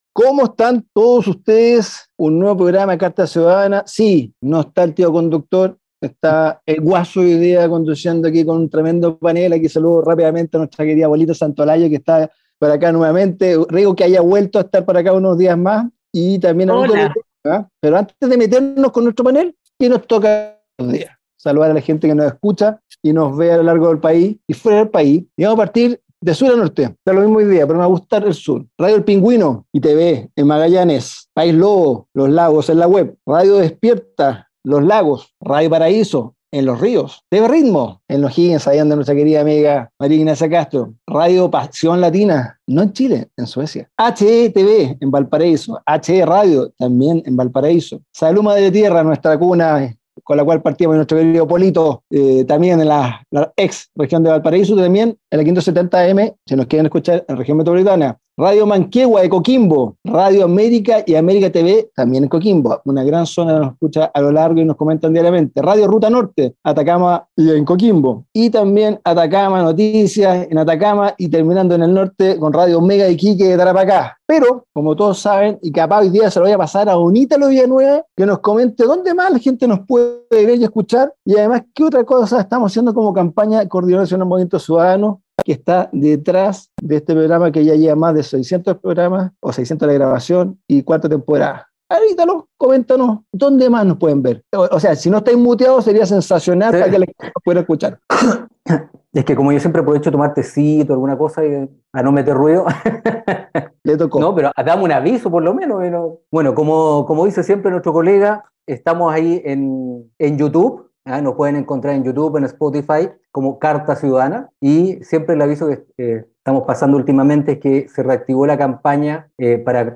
🎙 Carta Ciudadana es un programa radial de conversación y análisis sobre la actualidad nacional e internacional, emitido desde 2020 en más de 40 radios a lo largo de todo Chile.